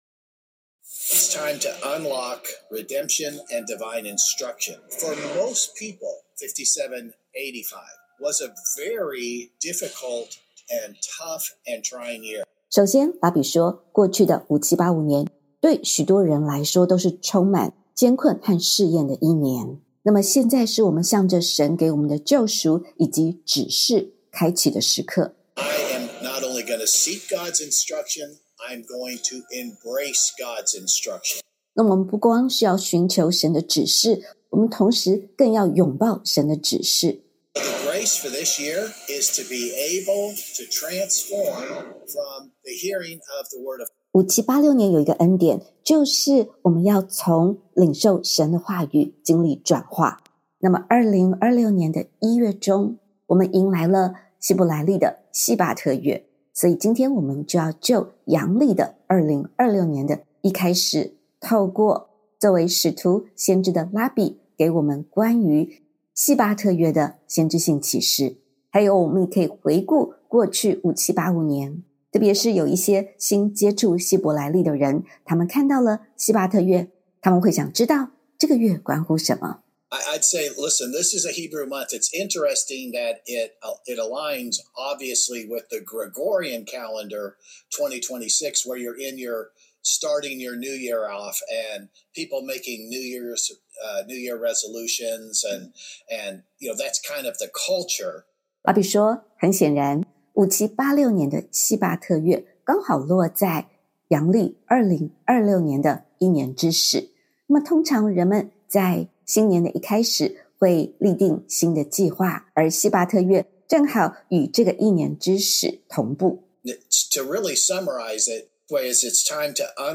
5786 年细罢特月 ~ 开启救赎，拥抱神的指示 音源 本篇信息是以访谈的形式进行，使我们能进入先知对于 5786 年刚好位在 2026 年新年阶段希伯来历的细罢特月的启示与行动中。